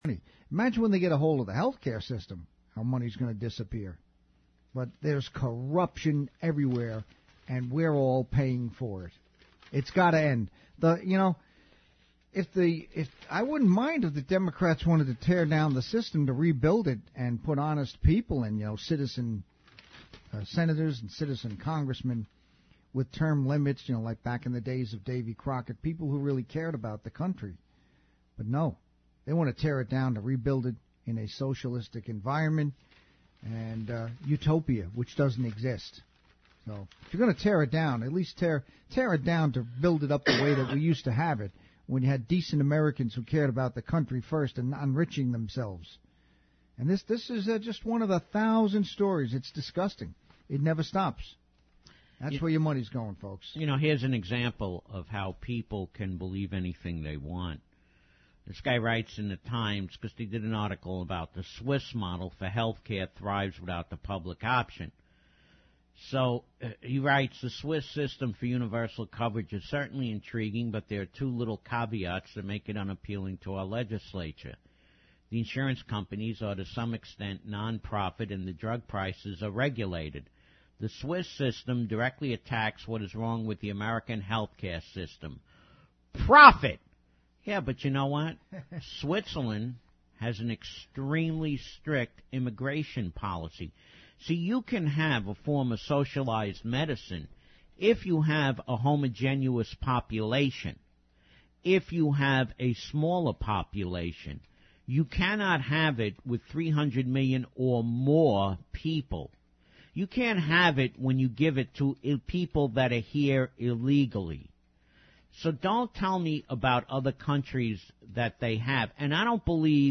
Tags: South Africa Propaganda Apartheid The Right Perspective Talk Radio